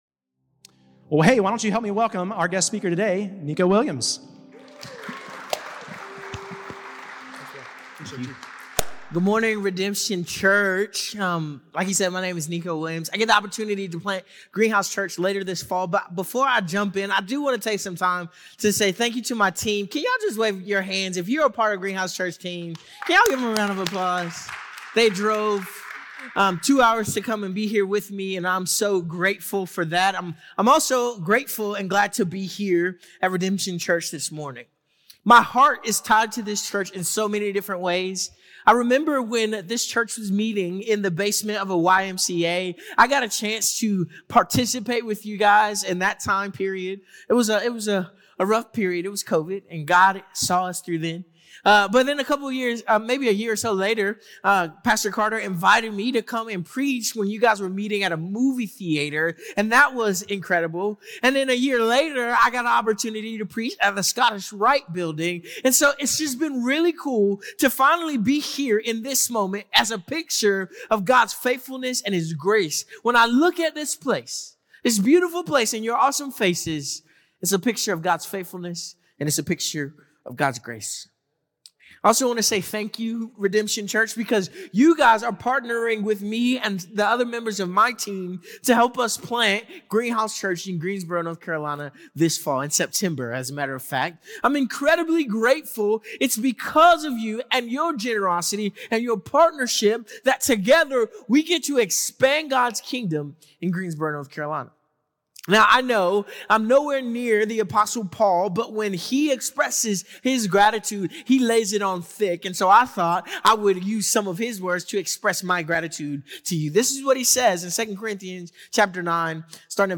Listen to Message
This week is Send Sunday at Redemption Church!